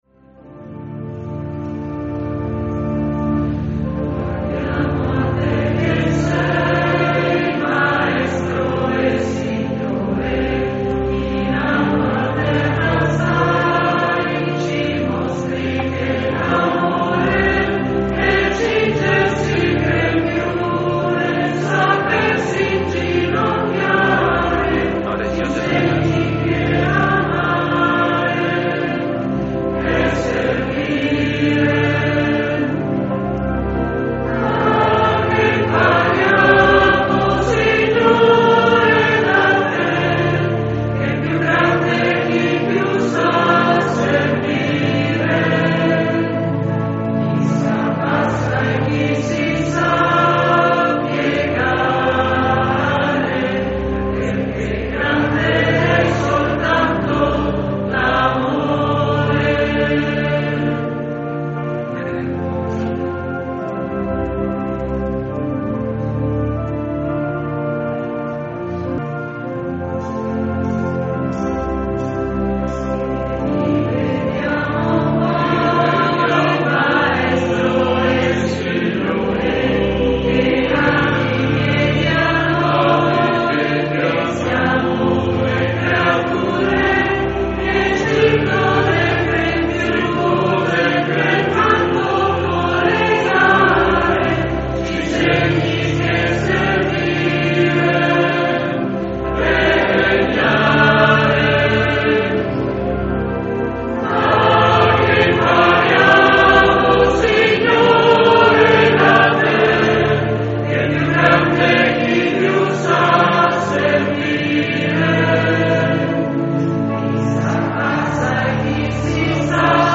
accompagnata dal Coro Interparrocchiale
CANTO ALL'OFFERTORIO